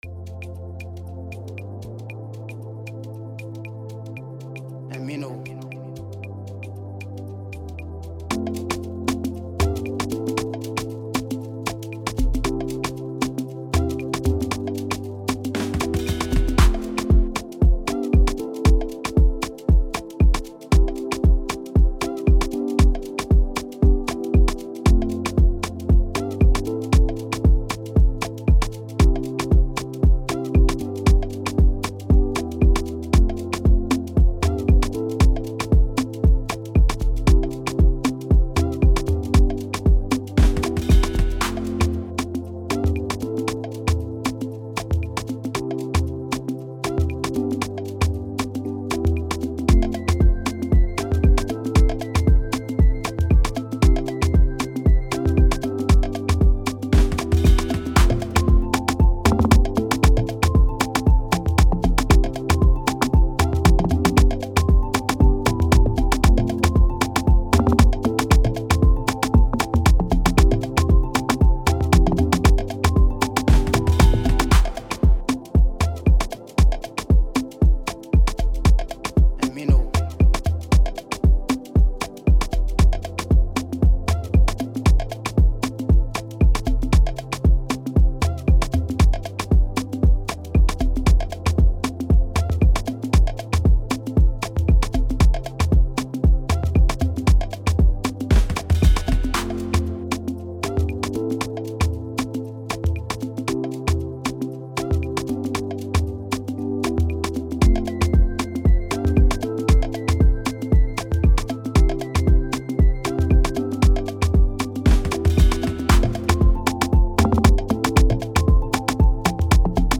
Afrobeat instrumentals
infectious rhythm